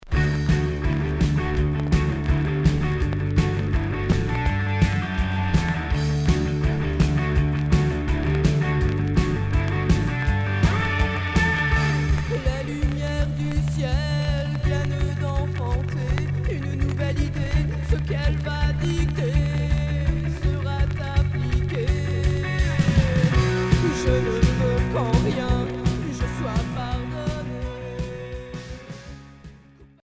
Rock Unique 45t retour à l'accueil